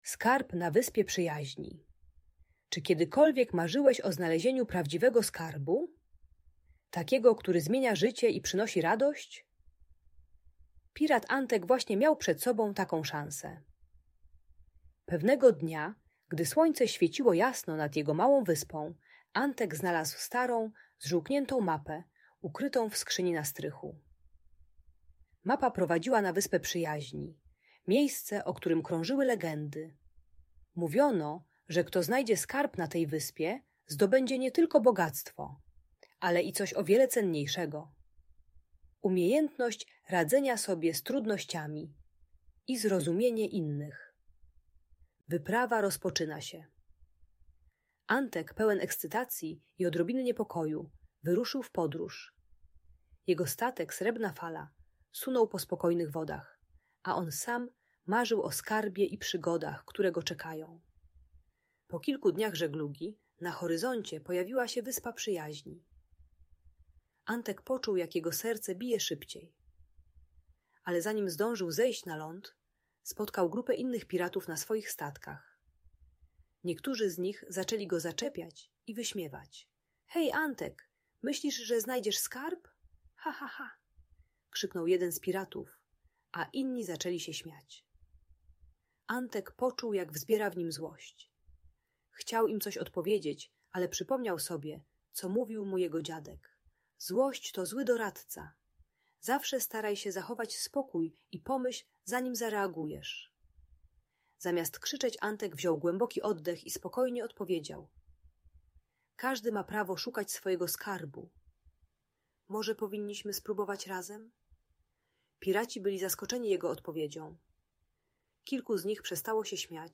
Skarb Przyjaźni - Bunt i wybuchy złości | Audiobajka